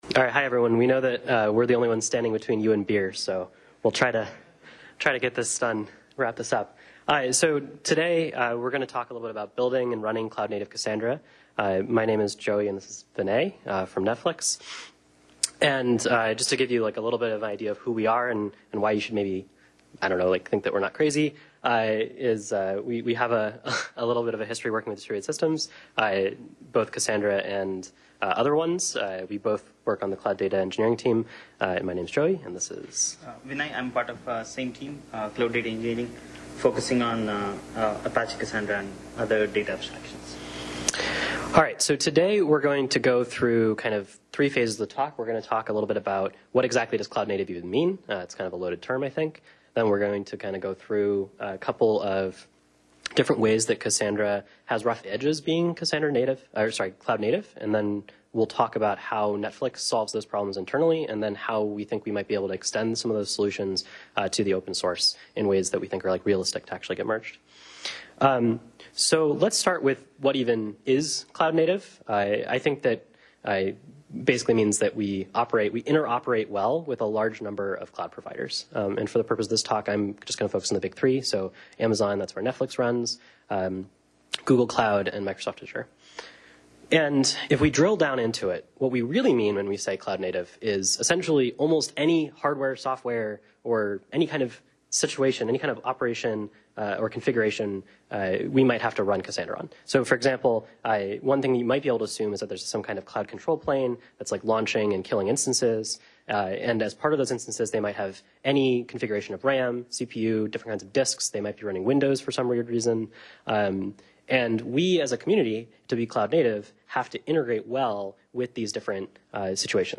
In this talk, we propose solutions to make building, deploying and monitoring Cassandra easy and low overhead, while taking advantage of cloud advancements wherever possible. https